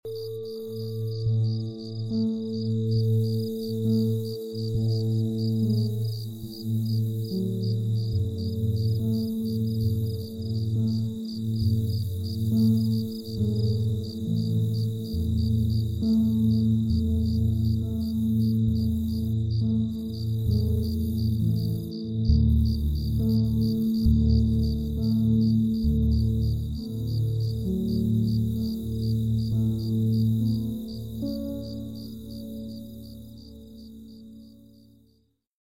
🌀 Elevate Your Vibration with 111 Hz! 🌀Step into a realm of spiritual awakening and deep healing with our 111 Hz frequency meditation.